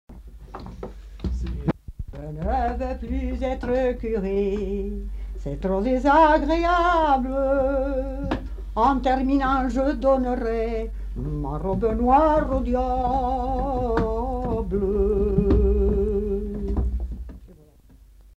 Lieu : Polastron
Genre : chant
Effectif : 1
Type de voix : voix de femme
Production du son : chanté